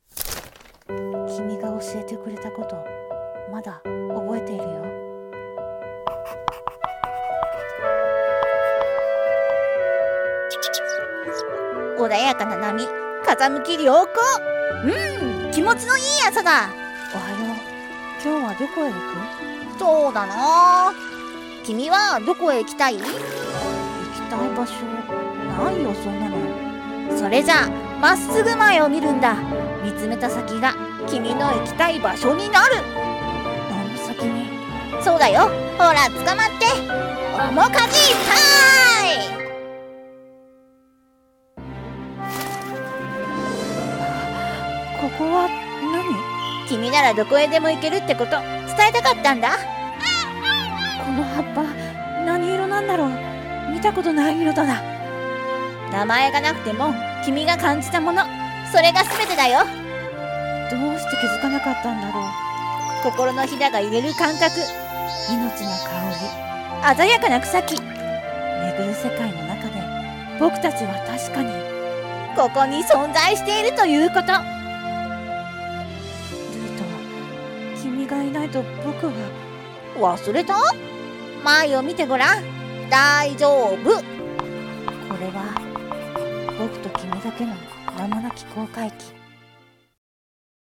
【声劇】名前のない航海記